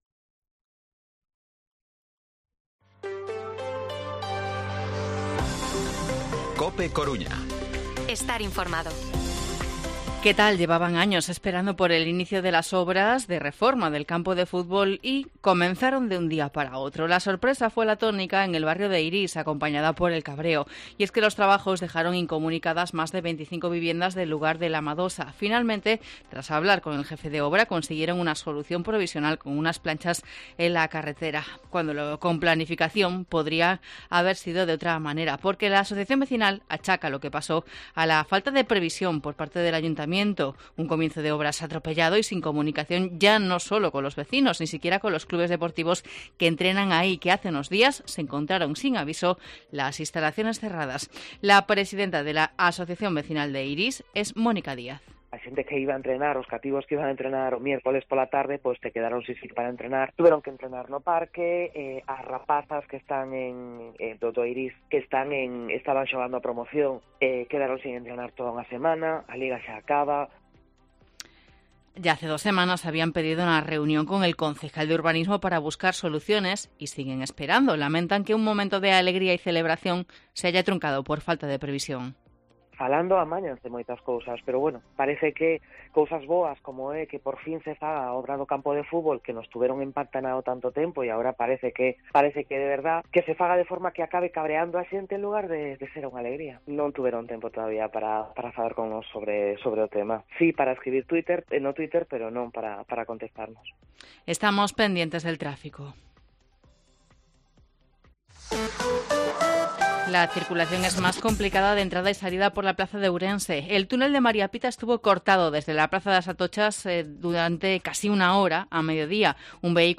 Informativo Mediodía COPE Coruña miércoles, 10 de mayo de 2023 14:20-14:30